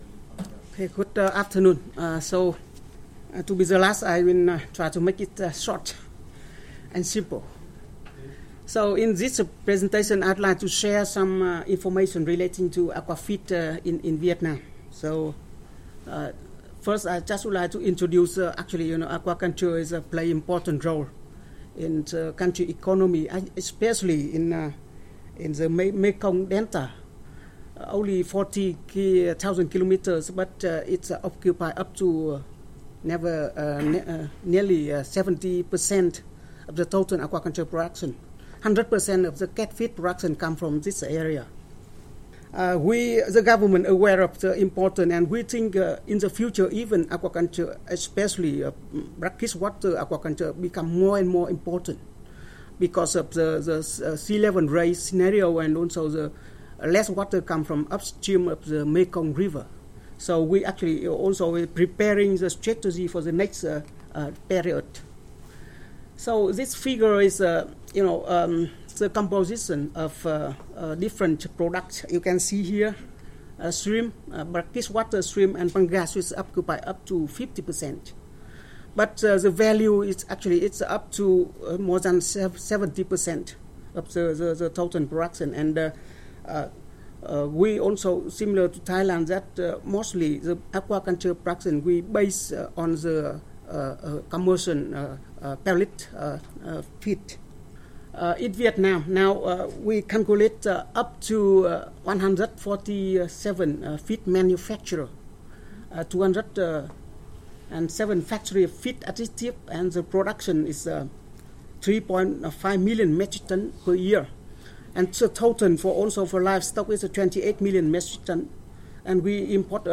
This presentation gives an overview of aquaculture feed production and related issues in Vietnam.
This collection contains audio recordings of the technical presentations made by experts, international organisations, the private sector and governments in the region.